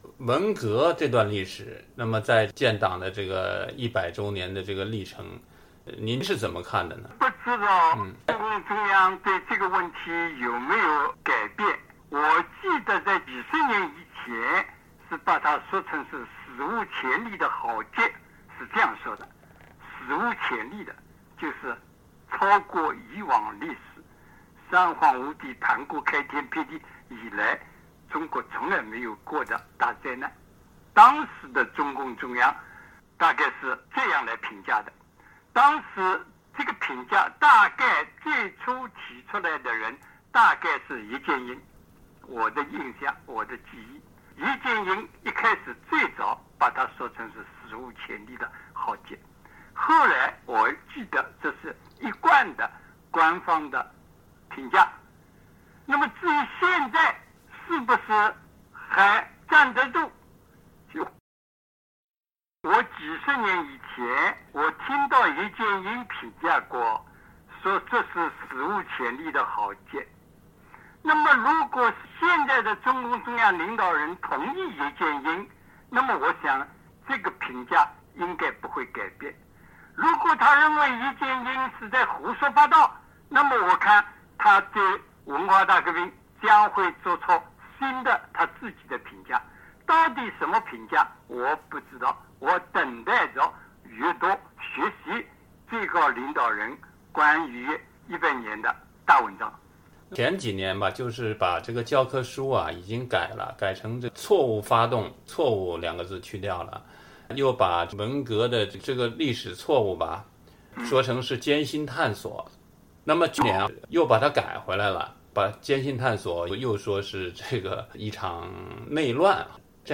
原中共中央委员、1989年北京武力镇压学生运动前曾担任赵紫阳政治秘书的鲍彤最近接受美国之音电话采访时回忆了对文革结束后重新担任领导职务的中共元老习仲勋的印象，以及与毛泽东以“利用小说反党”的罪名迫害习仲勋有关的一些往事。